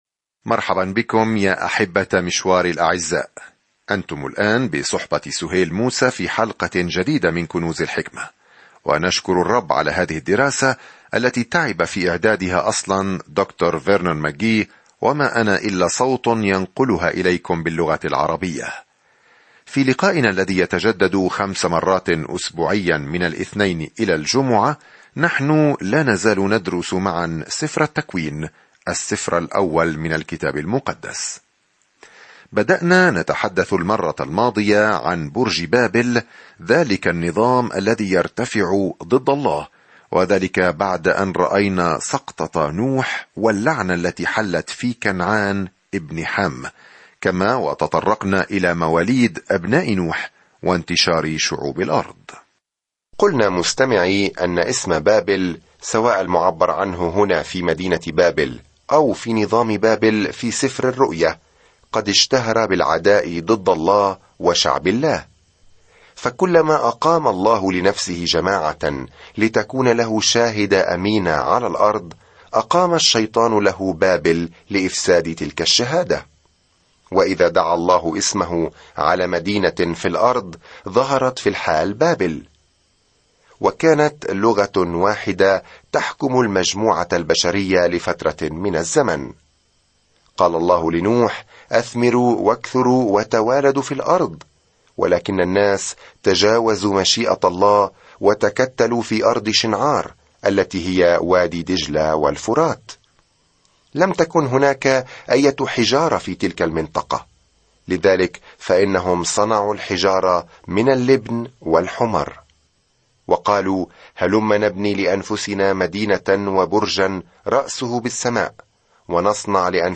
الكلمة اَلتَّكْوِينُ 5:11-32 يوم 14 ابدأ هذه الخطة يوم 16 عن هذه الخطة هذا هو المكان الذي يبدأ فيه كل شيء – الكون، الشمس والقمر، الناس، العلاقات، الخطيئة – كل شيء. سافر يوميًا عبر سفر التكوين وأنت تستمع إلى الدراسة الصوتية وتقرأ آيات مختارة من كلمة الله.